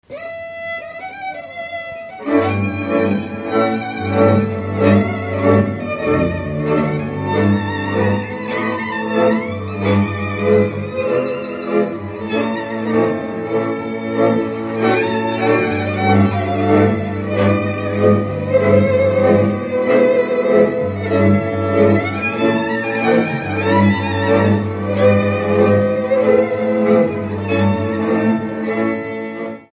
Dallampélda: Hangszeres felvétel
Erdély - Szilágy vm. - Selymesilosva
hegedű
kontra
brácsa
bőgő
Műfaj: Lakodalmas
Stílus: 6. Duda-kanász mulattató stílus